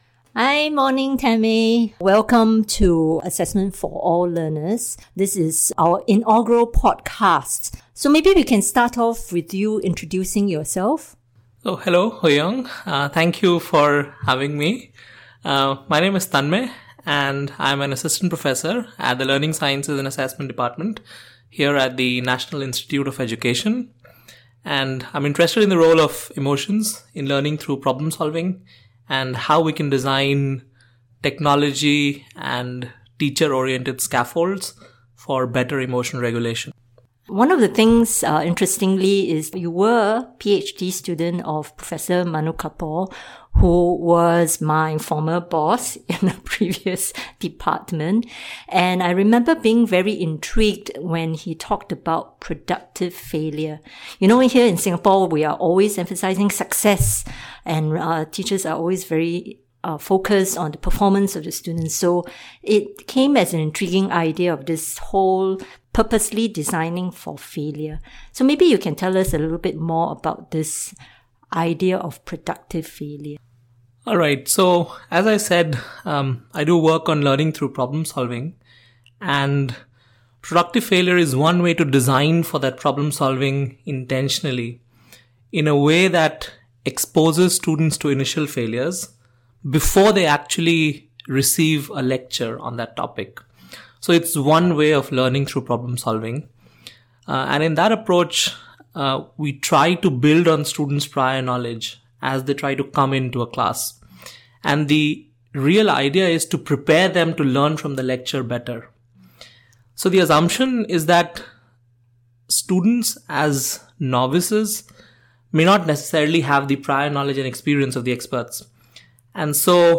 How to leverage failure for learning - A Conversation